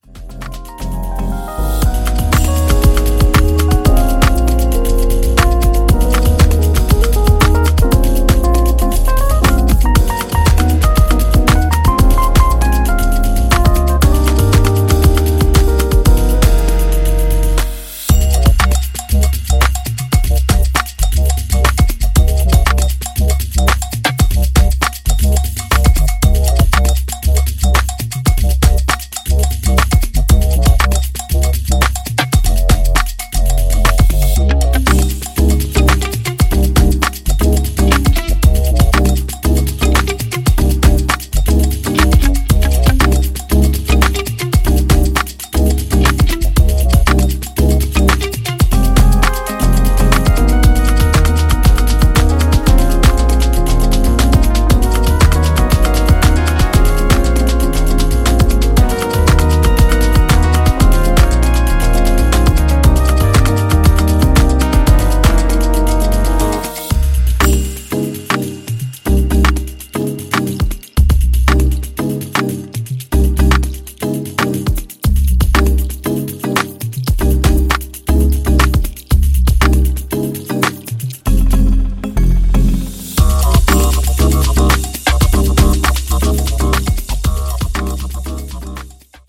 ジャンル(スタイル) DEEP HOUSE / BROKEN BEAT